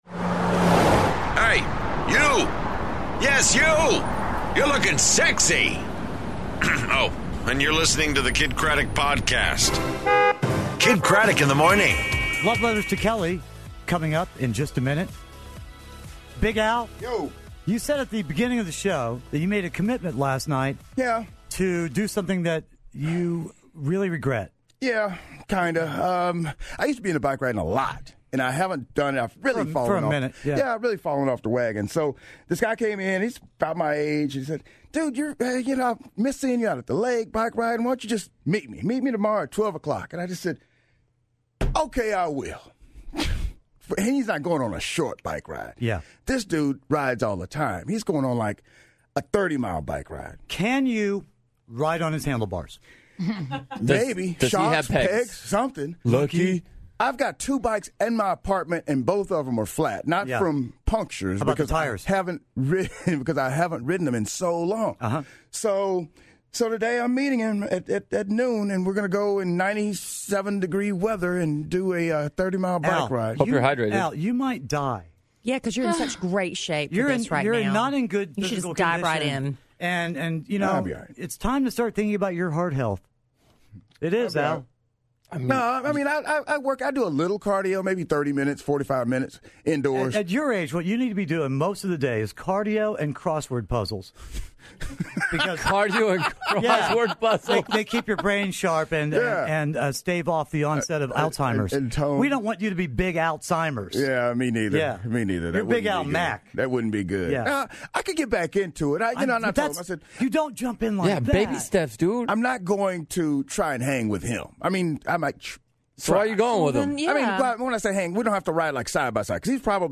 and Seth Rogen on the phone!